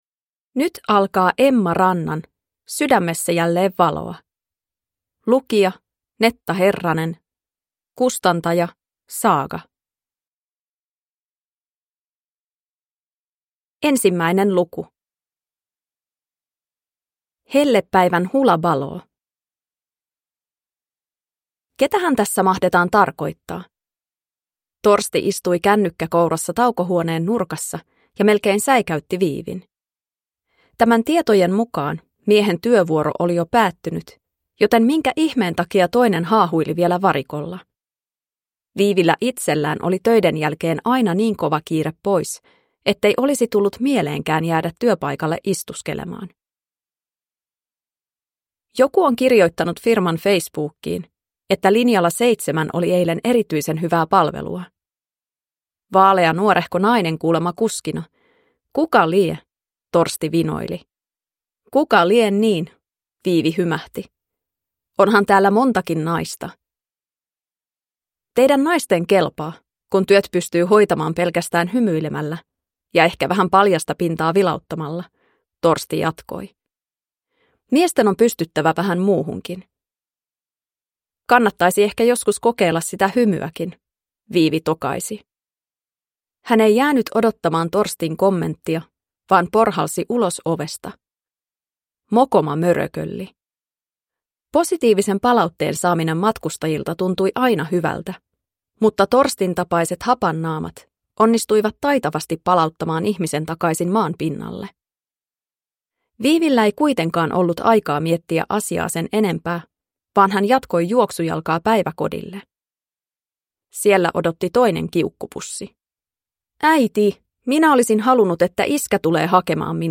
Sydämessä jälleen valoa (ljudbok) av Emma Ranta